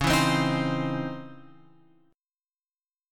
C#mM9 chord {9 7 10 8 x 8} chord